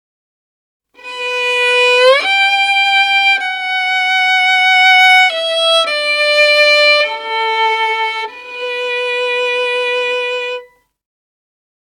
Saddest_Violin_3
cinema famous film funny hearts-and-flowers motif movie sad sound effect free sound royalty free Movies & TV